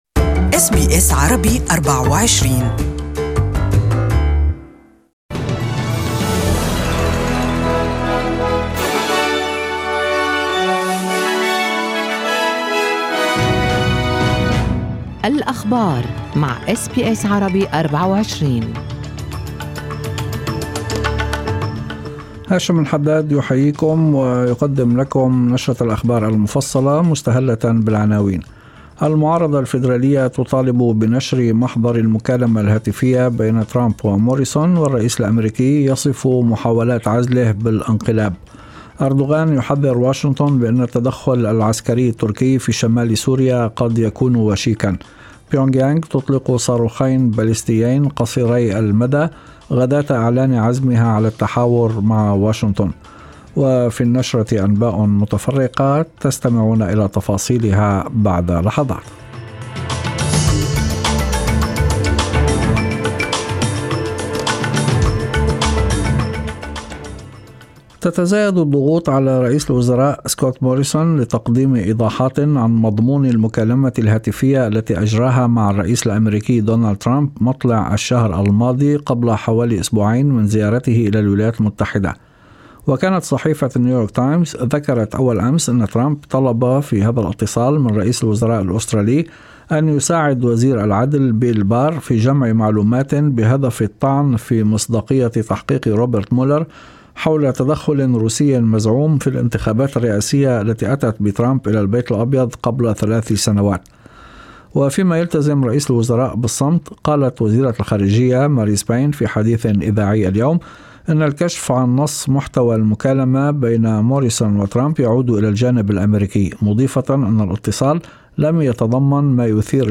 Evening News: Labor pressures the PM to release transcript of Donald Trump phone call